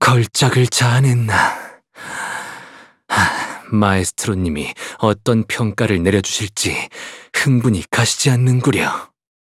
Have a guess at what sinner and identity this voiceline belongs to!